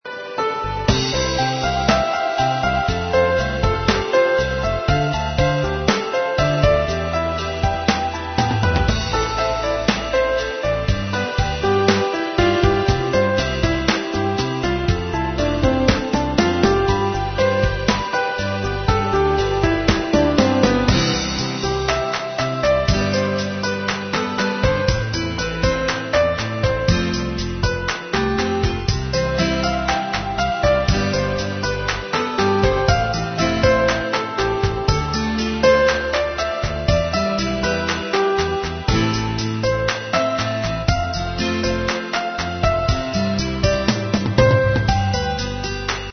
Slow and relaxed Instrumental composition